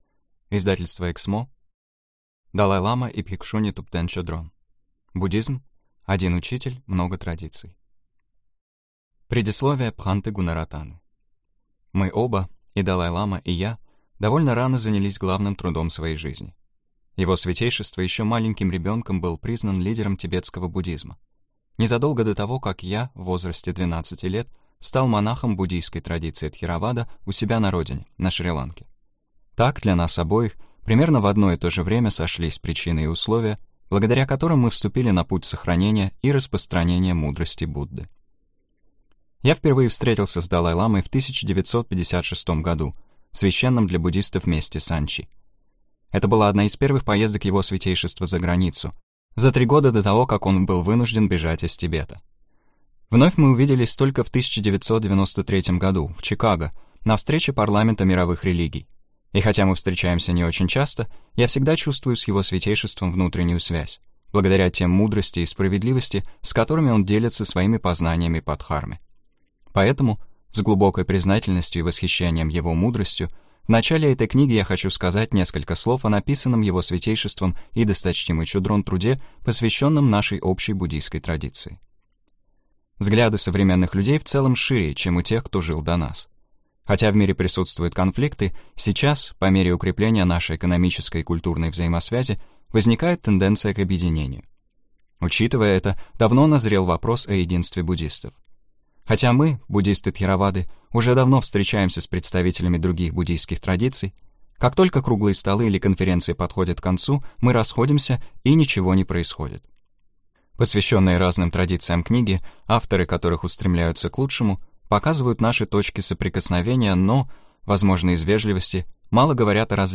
Аудиокнига Буддизм. Один учитель, много традиций | Библиотека аудиокниг